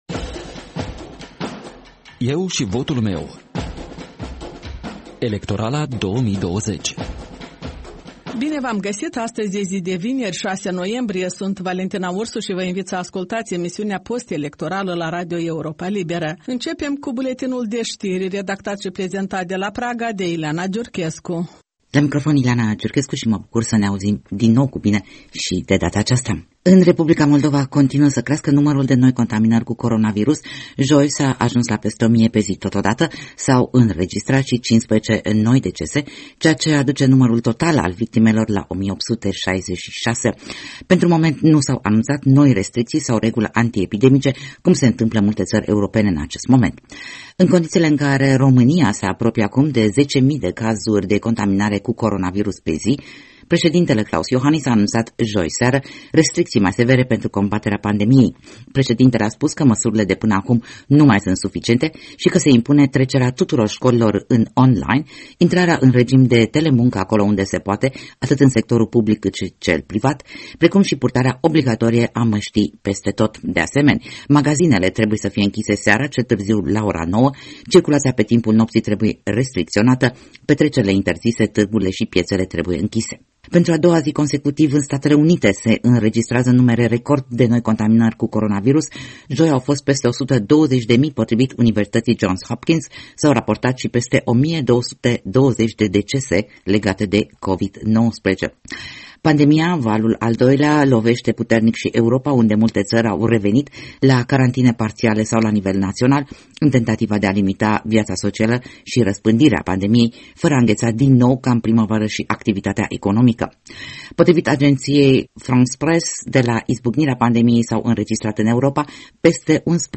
De luni până vineri, de la ora 13.00, radio Europa Liberă prezintă interviuri cu candidații în alegerile prezidențiale din 15 noiembrie, discuții cu analiști și formatori de opinie, vocea străzii și cea a diasporei.